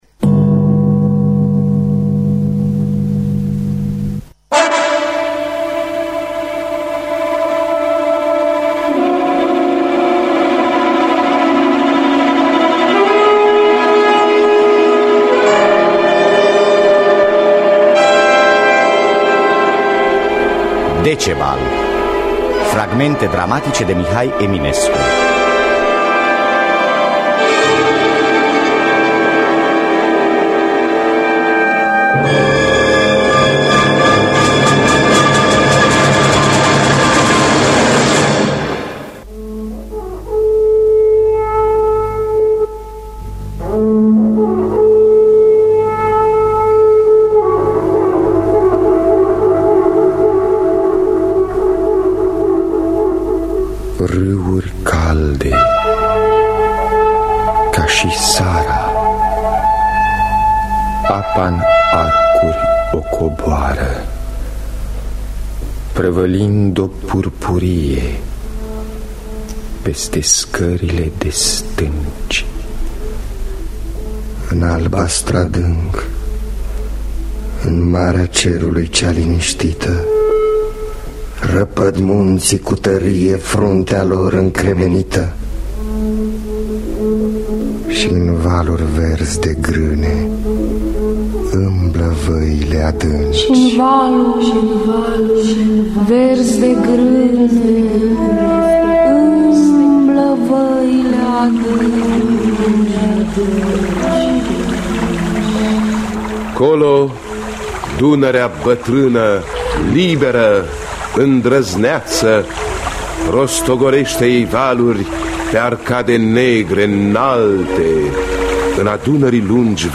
Teatru Radiofonic Online
Adaptare radiofonică